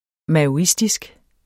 Udtale [ mæoˈisdisg ]